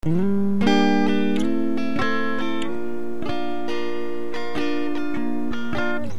Original Unprocessed Signal